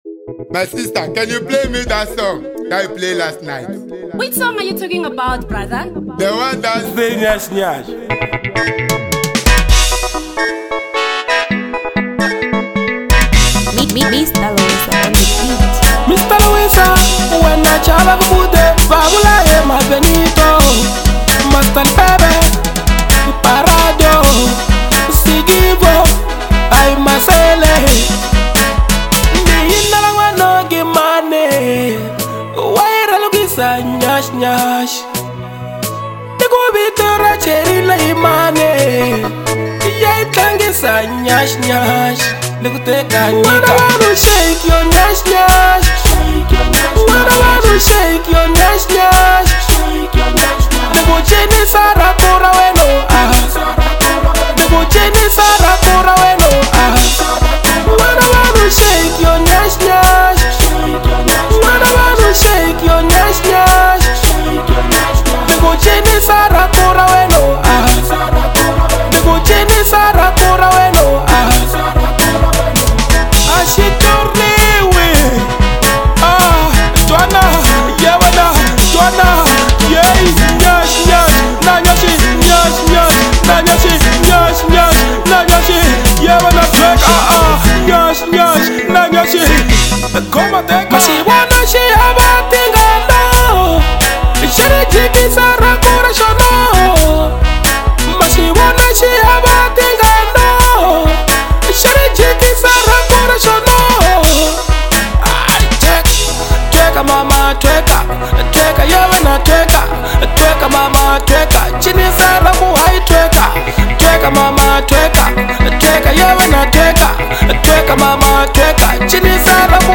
Bolo House